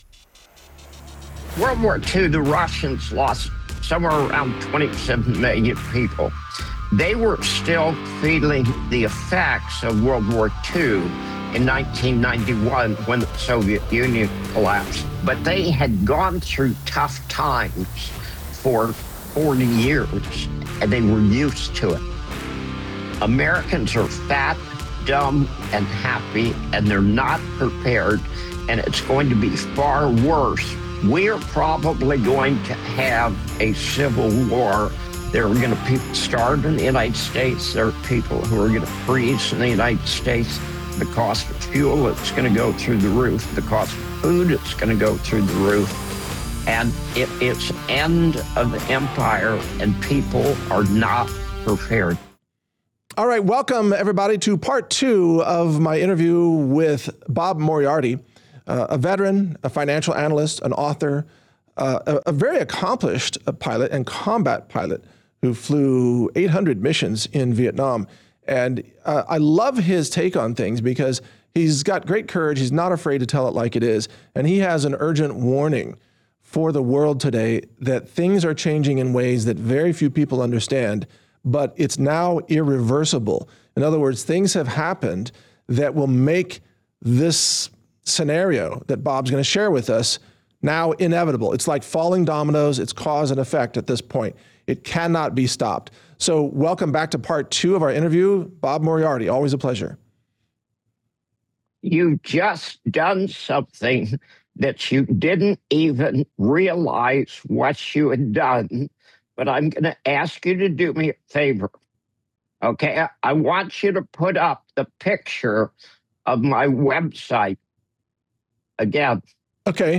Interview: The Financial Collapse That Changes Everything (Part 2)